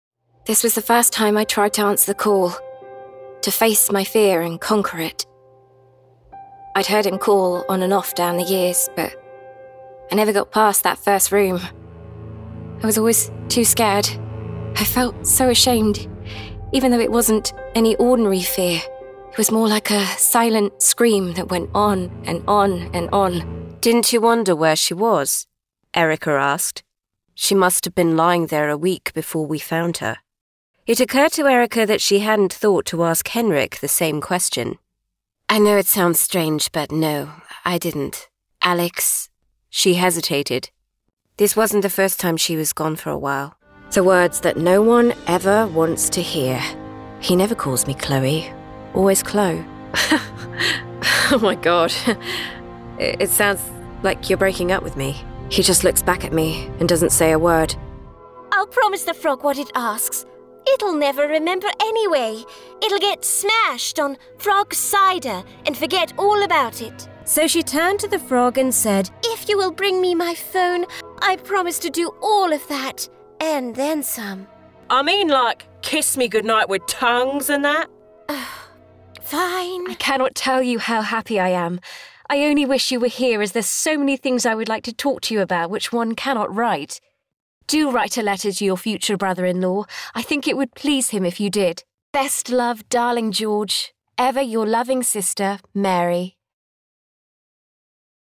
Audiolibros
Micrófonos: Neumann U87, Neumann TLM 102, Sennheiser MKH 416
Cabina: Cabina vocal de doble pared a medida de Session Booth con paneles añadidos de EQ Acoustics, Auralex y Clearsonic.